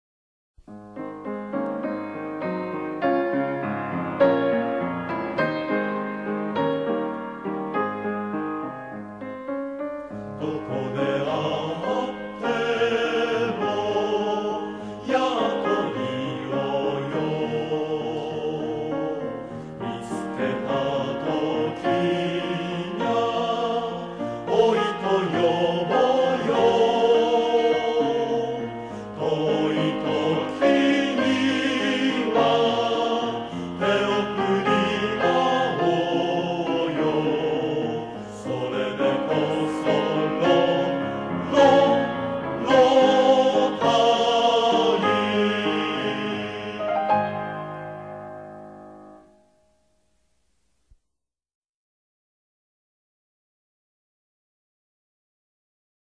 soredekoso-rotary_Vo-1_Pf.mp3